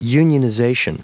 Prononciation du mot unionization en anglais (fichier audio)
Prononciation du mot : unionization